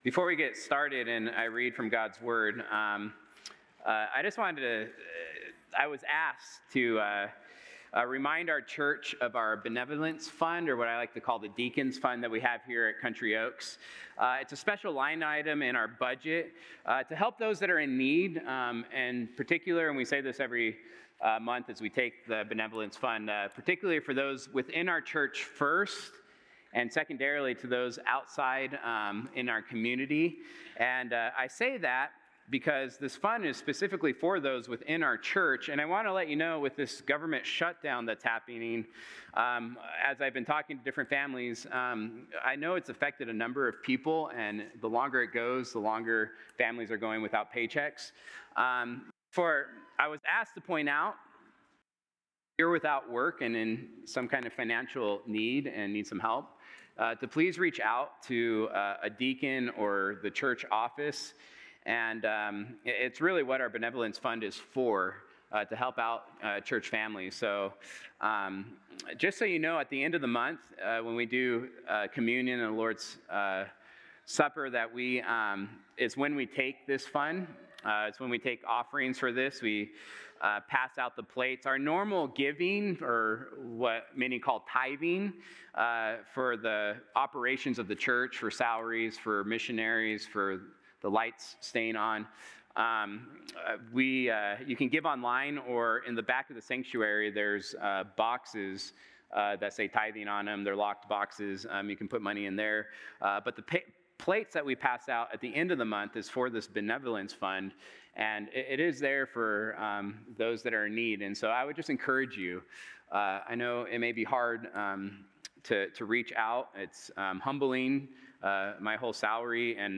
Sunday-Sermon-November-9-2025.mp3